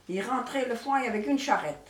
Mémoires et Patrimoines vivants - RaddO est une base de données d'archives iconographiques et sonores.
Langue Maraîchin
Catégorie Locution